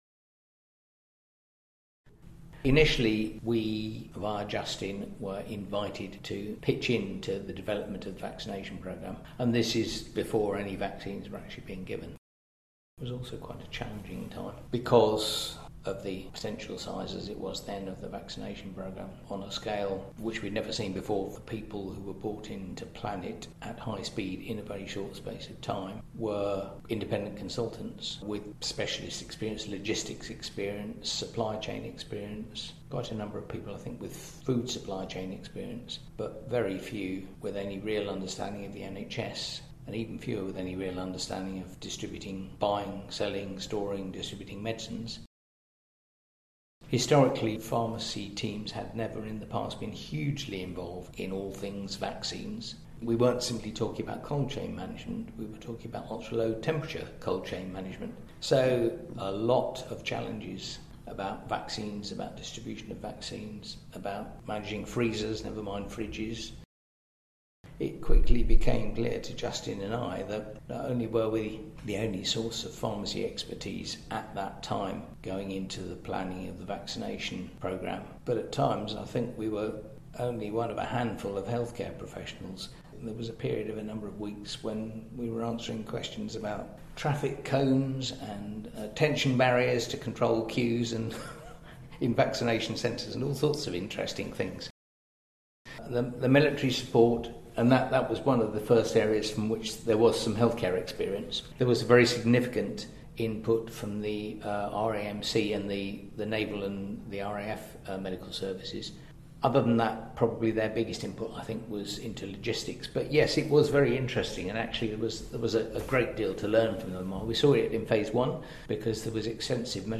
RCPharms Museum has a growing collection of oral history recordings where pharmacists past and present share their experiences in their own words.